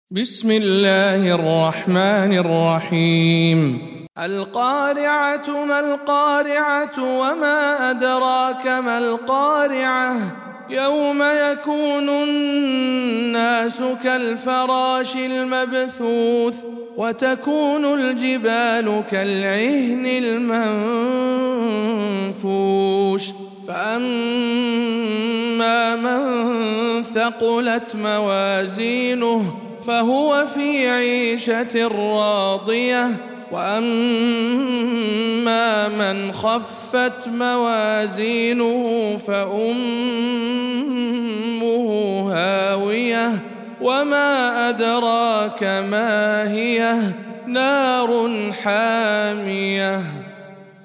سورة القارعة - رواية روح عن يعقوب